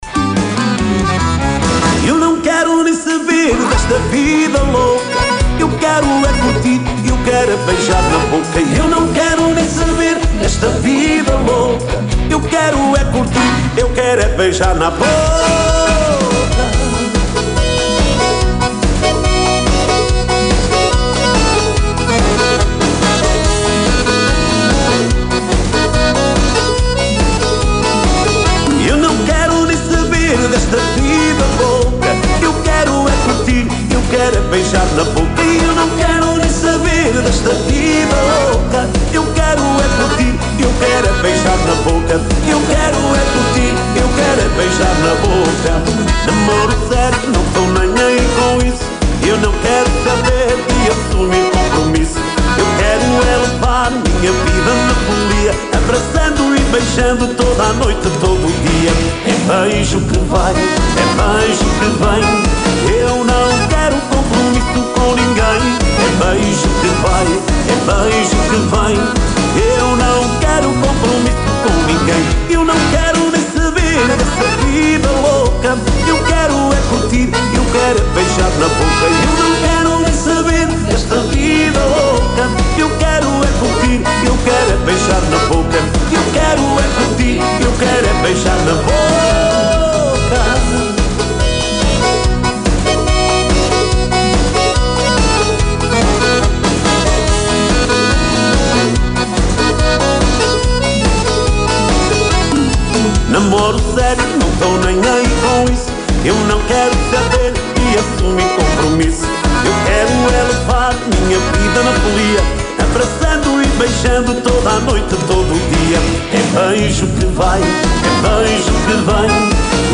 ENTREVISTA-DOCE-MEL.mp3